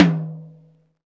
TOM XTOMMI0E.wav